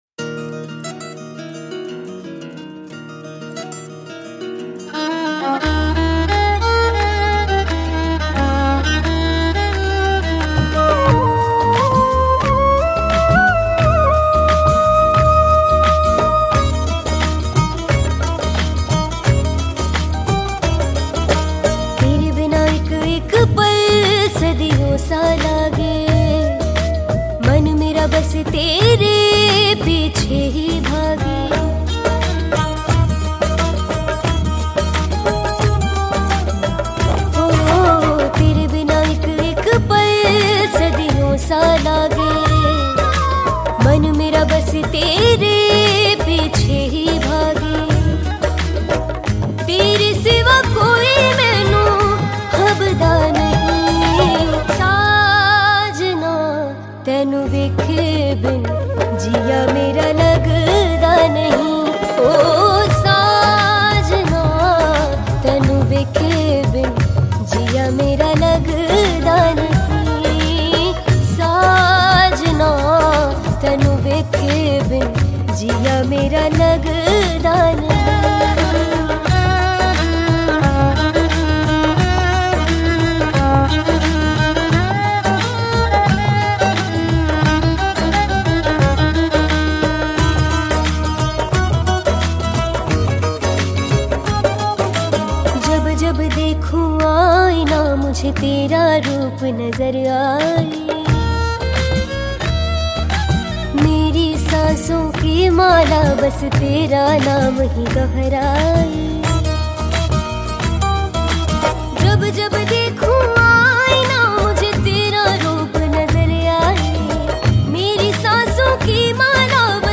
Hindi Pop